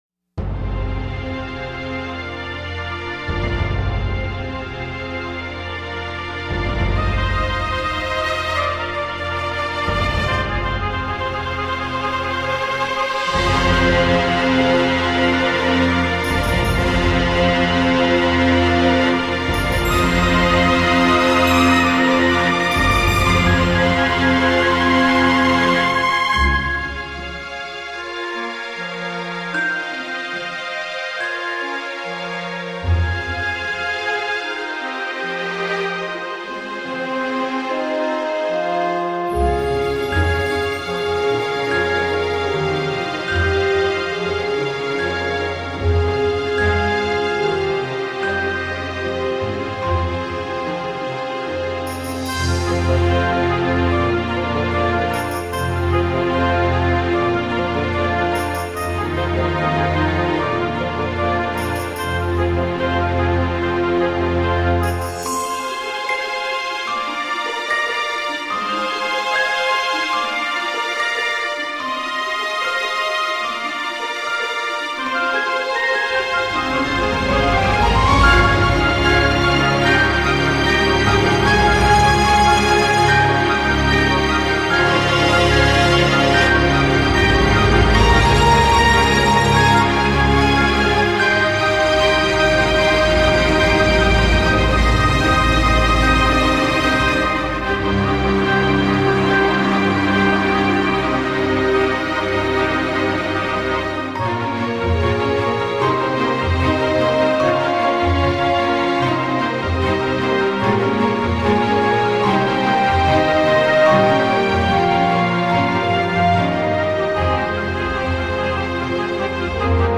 written in F minor